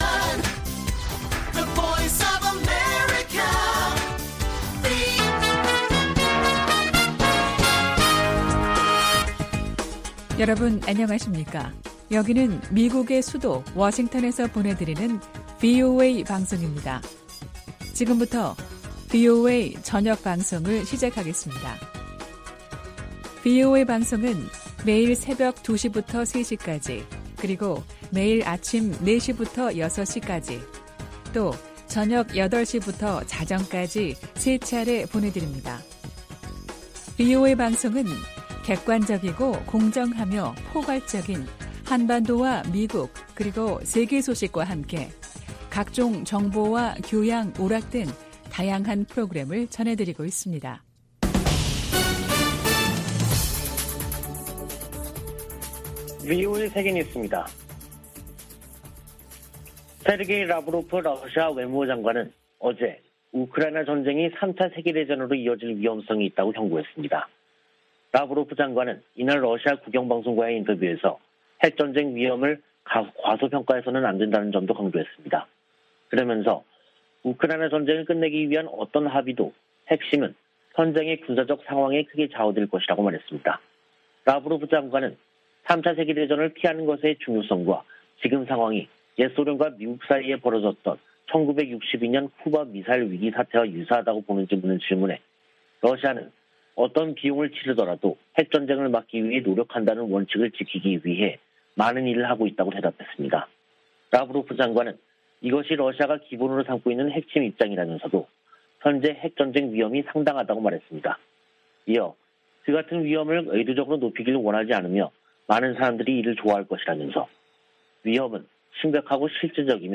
VOA 한국어 간판 뉴스 프로그램 '뉴스 투데이', 2022년 4월 26일 1부 방송입니다. 북한이 25일 핵 무력을 과시하는 열병식을 개최했습니다.